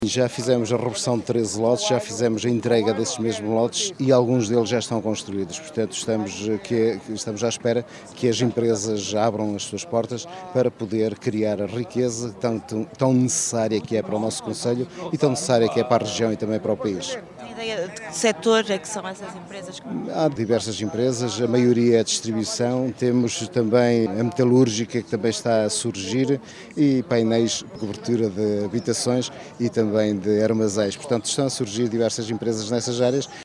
A novidade fez parte do discurso das comemorações dos 42 anos do 25 de Abril.
Declarações esta manhã, nas comemorações oficiais do 25 de Abril, que começaram domingo à noite, com o concerto “Fado e Cantigas de Abril”.